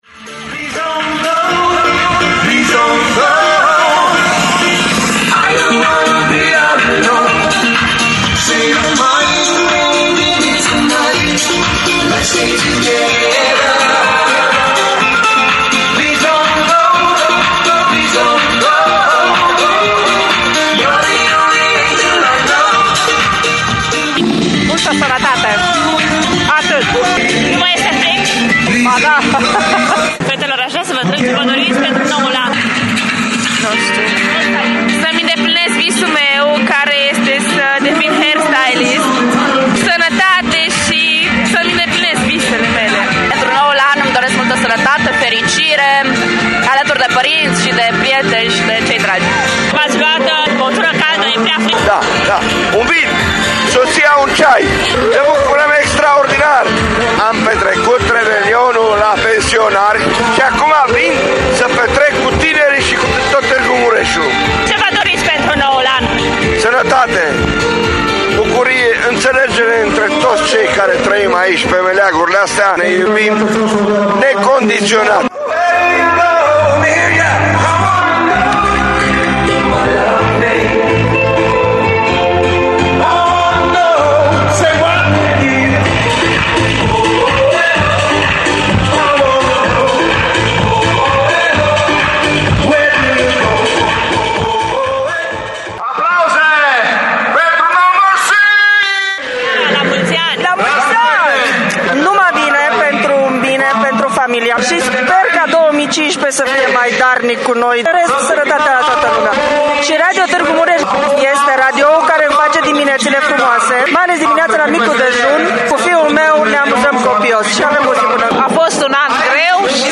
Extrem de puține grade în termometre și mulți decibeli au fost în noaptea dintre ani la Tîrgu-Mureș unde circa 2.000 de oameni au petrecut pe ritmurile de dans ale binecunoscutei trupe pop No Mercy.